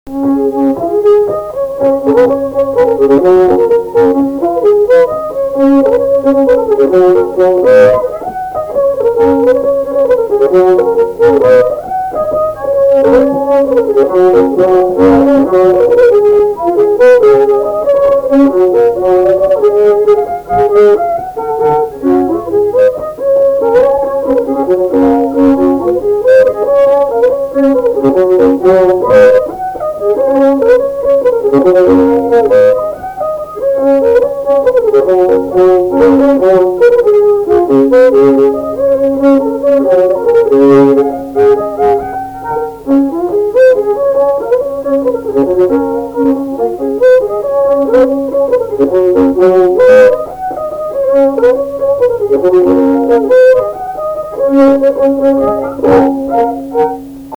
Krakoviakas
šokis